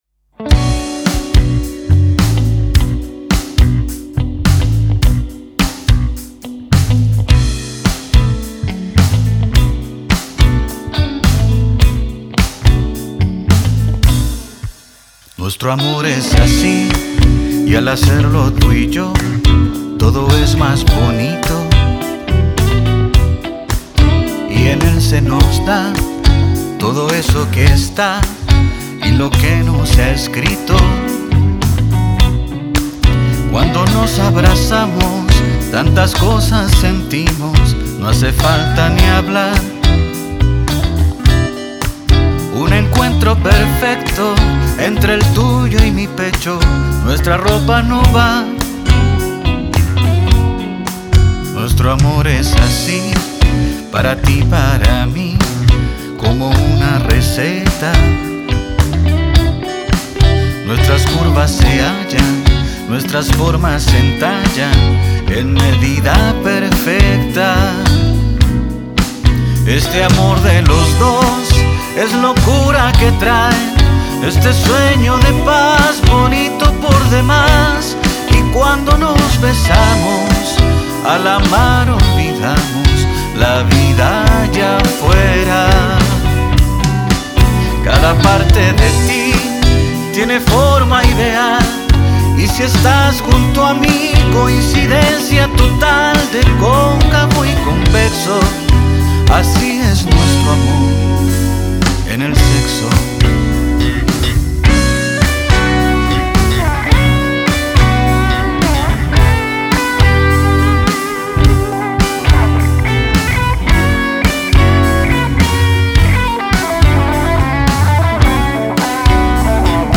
El cantautor chileno reinventa el clásico de 1984